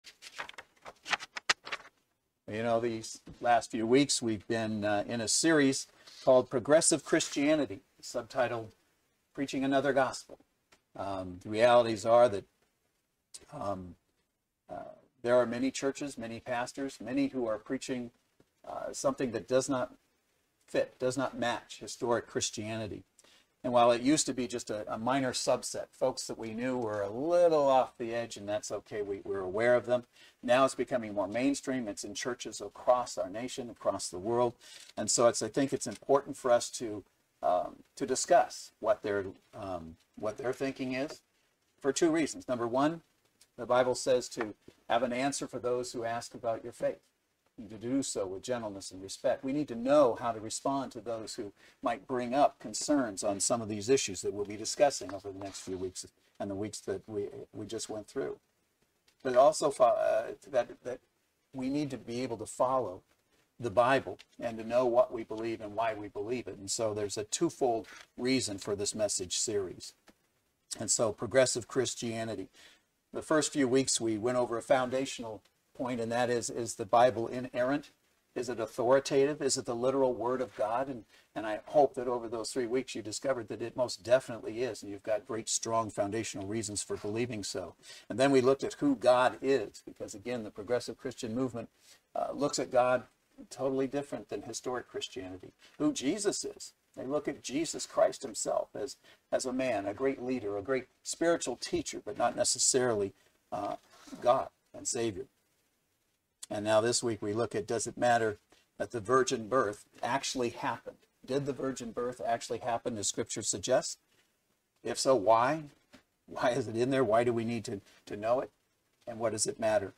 Service Type: Saturday Worship Service The greatest danger to Christians today comes not from outside the Church – but from within.